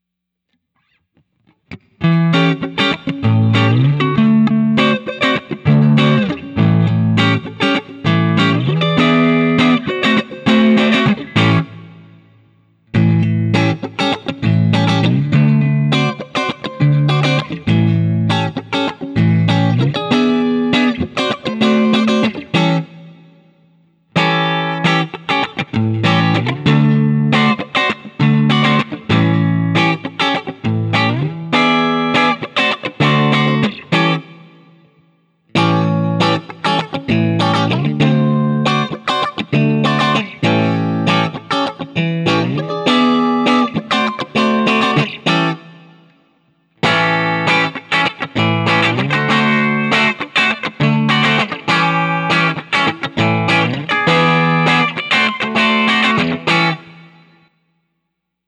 7th Chords
For the first five recordings I had the compressor on and I think it made everything sound a bit flat and harsh because as soon as I turned it off I liked it better, but I’m too lazy to re-record.
For most of the recordings I play my usual test chords through all five of the pickup settings in the following order: